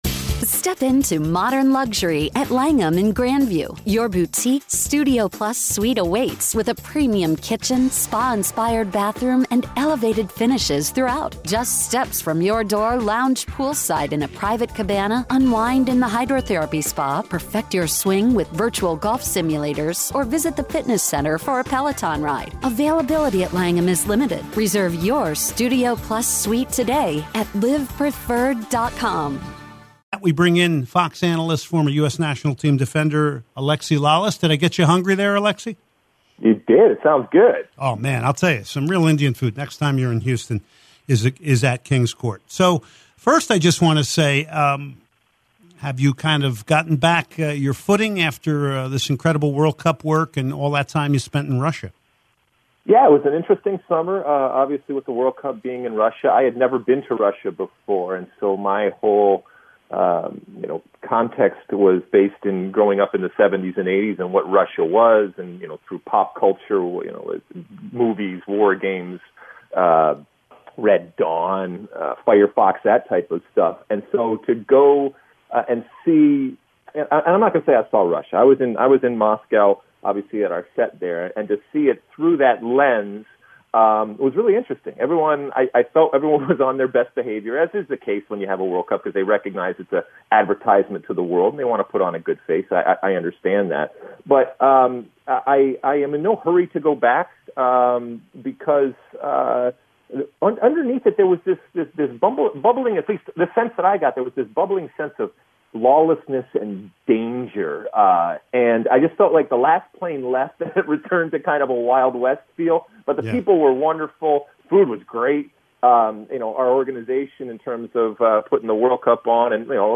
09/04/2018 Soccer Matters: Alexi Lalas Interview